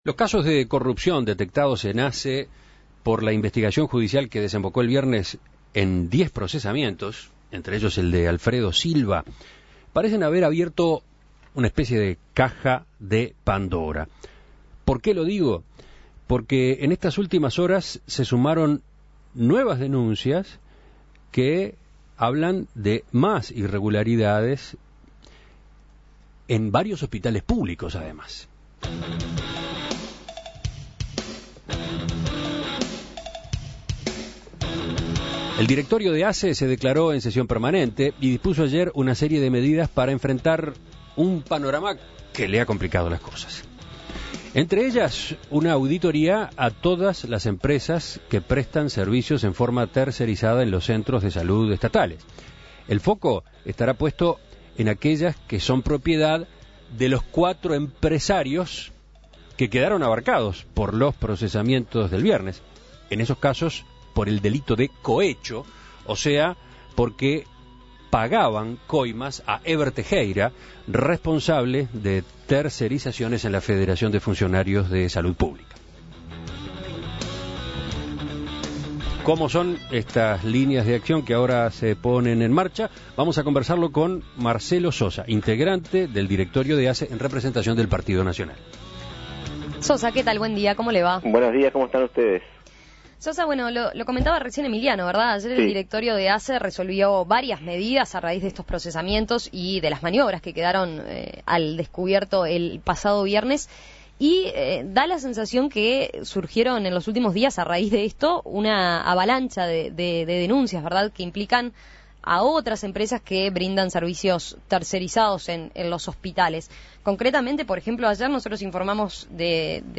Una semana después de conocerse los casos de sobrefacturación de la cooperativa de limpieza Buena Estrella y los procesamientos de integrantes del Directorio de ASSE, han surgido nuevas denuncias relativas a irregularidades en contrataciones de servicios. La oposición en el directorio de la administración de salud solicitó realizar una auditoría a todas las empresas mercerizadas que estén trabajando en ASSE. En Perspectiva entrevistó a Marcelo Sosa, representante nacionalista en el Directorio de ASSE, quien está evaluando solicitar nuevas investigaciones administrativas.